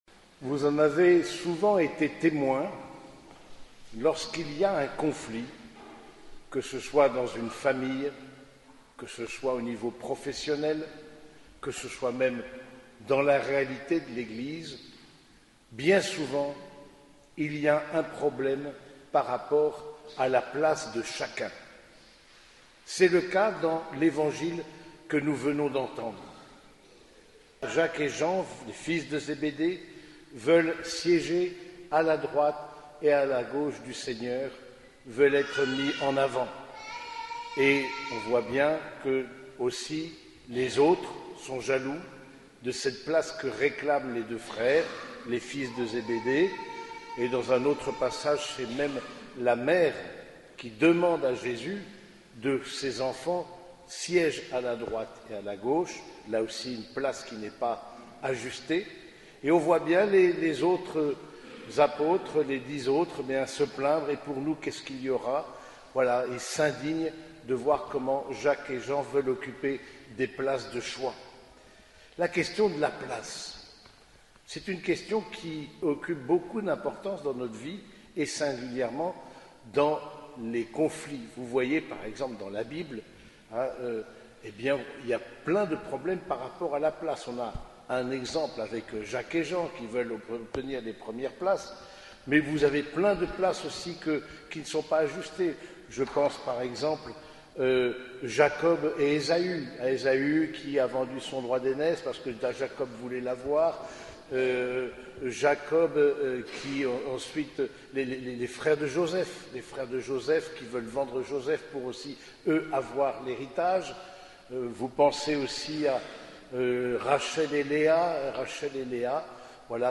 Homélie du 29e dimanche du Temps Ordinaire